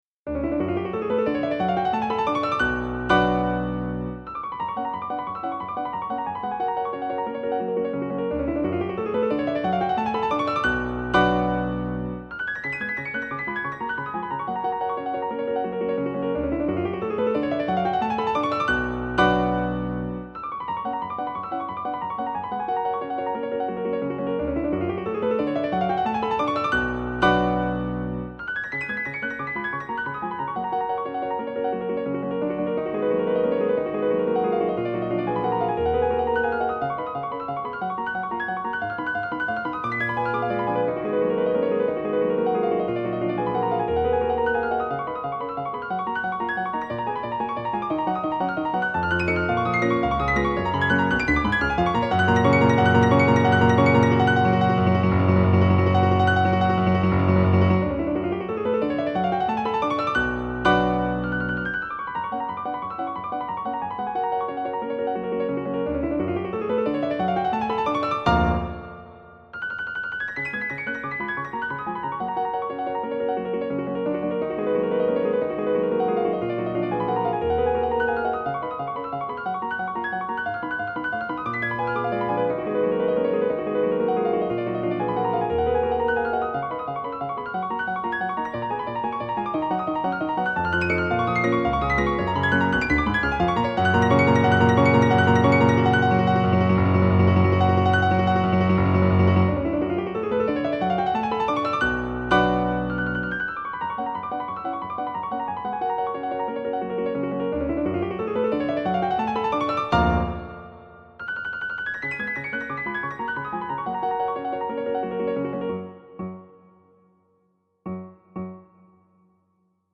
クラシックピアノから私のお気に入りをmidiで打ち込んでみました。
mp3 若々しさを全面に押し出した活気溢れる快作です。楽譜を見るだけでは結構簡単そうなのに……。めっちゃ速いです。